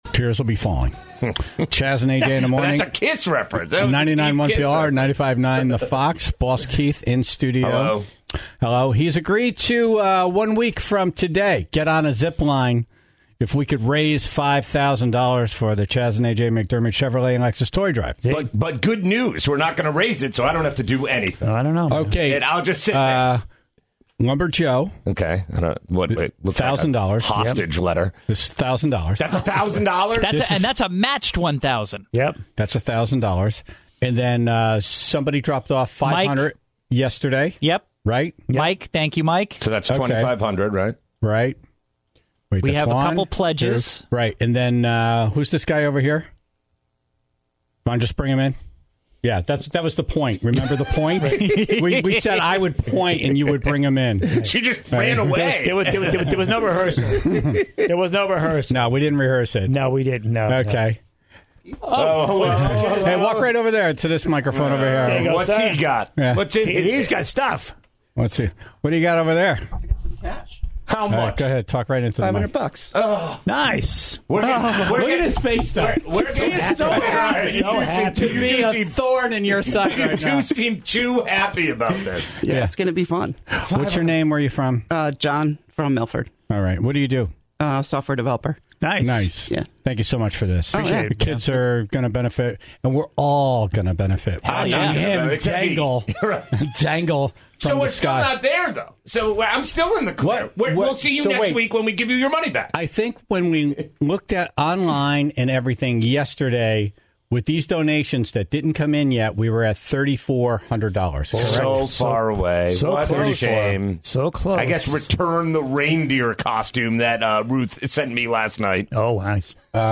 in studio every Friday for a Top 5 list